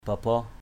/ba-bɔh/ (d.) sâu đọt dừa, đuông dừa.